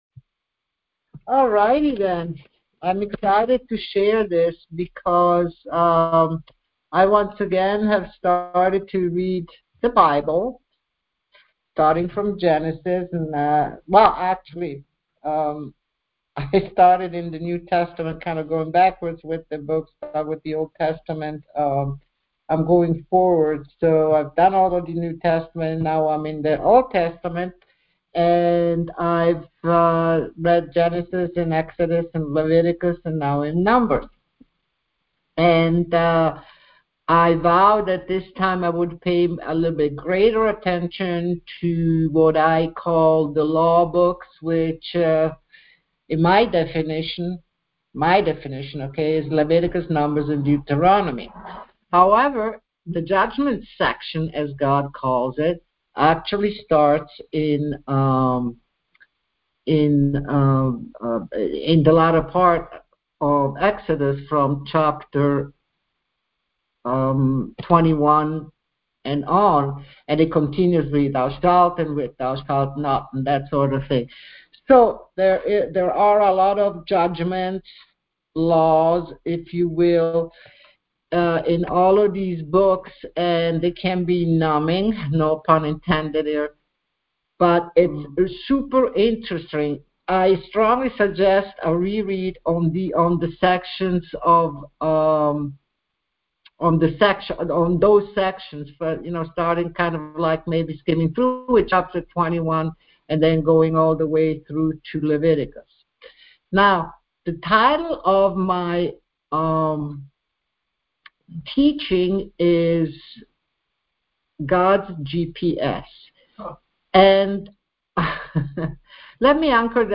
Church in San Diego California
Details Series: Conference Call Fellowship Date: Monday, 27 October 2025 Hits: 177 Scripture: Proverbs 3:5 Play the sermon Download Audio ( 10.15 MB )